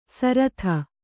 ♪ saratha